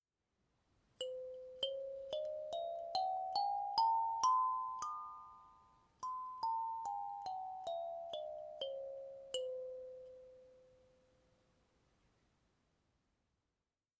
すべてがユニークで個性的な音階。
本体木材：タモ・チューリップウッド
キー：ピアノ線　／　金具：鉄・真鍮